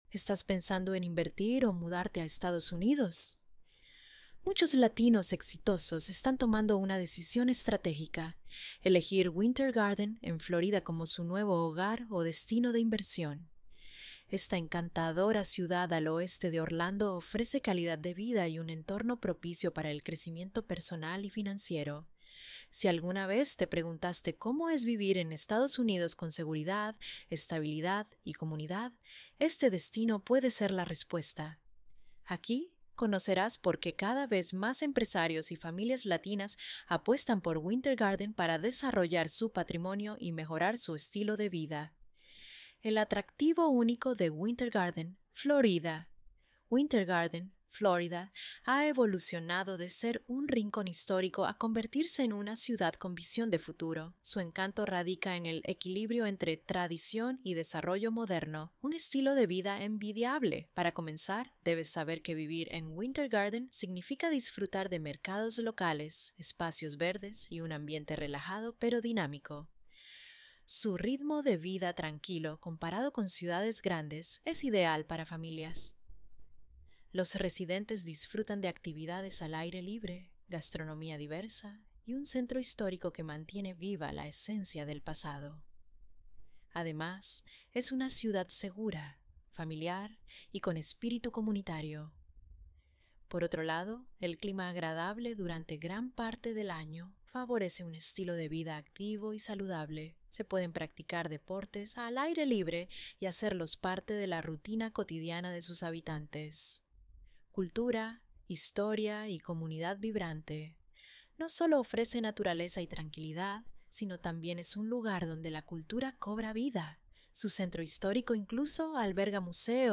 ▶ Escucha el artículo aquí: Winter Garden